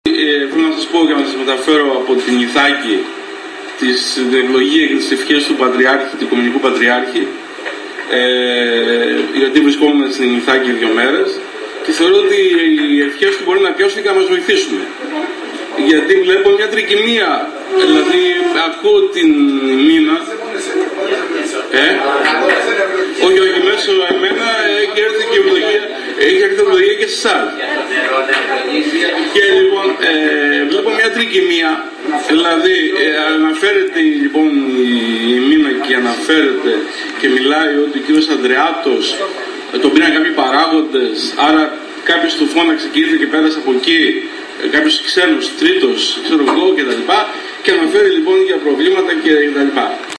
Συνεδρίαση στην αίθουσα του Επιμελητηρίου.